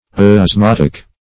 \os*mot"ic\